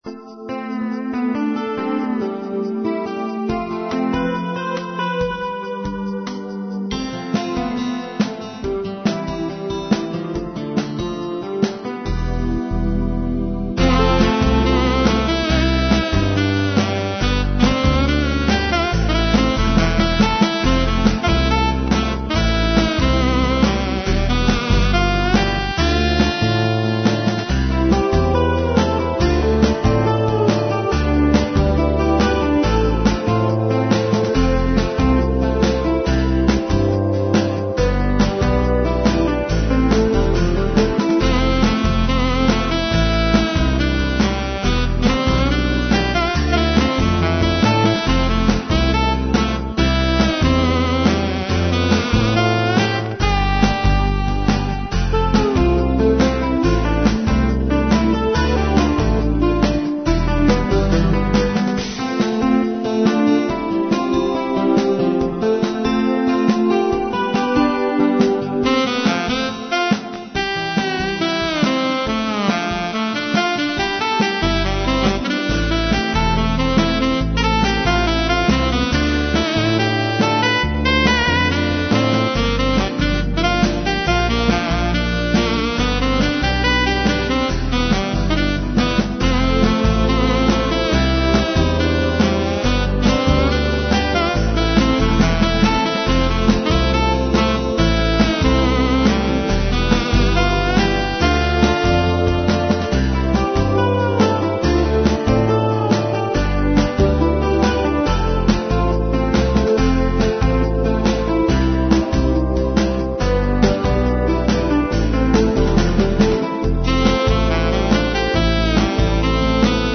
Dramatic Pop Rock Ballad with feel of joy